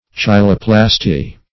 Search Result for " cheiloplasty" : The Collaborative International Dictionary of English v.0.48: Cheiloplasty \Chei"lo*plas`ty\, n. [Gr.